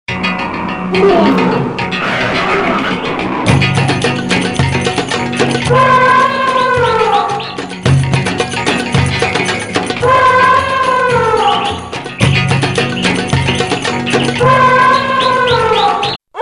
Elephant sounds free ringtone free download
Animals sounds